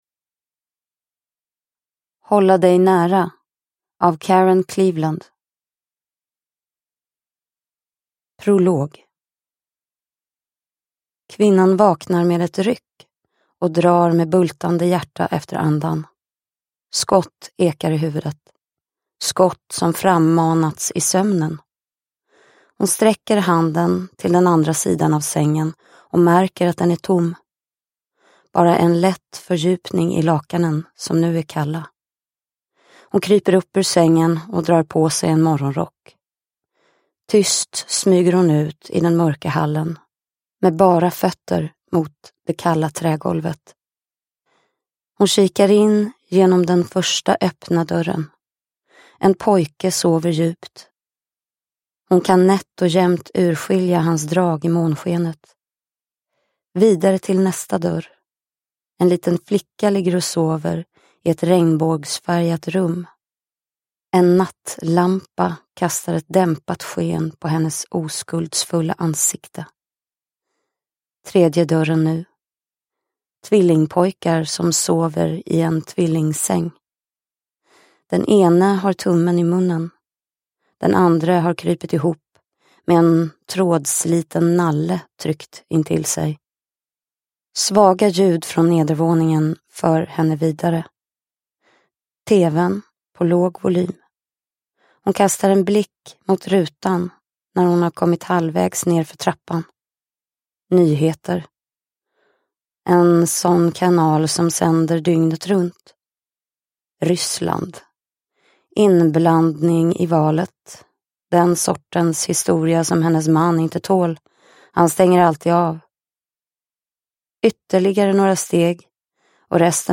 Hålla dig nära – Ljudbok – Laddas ner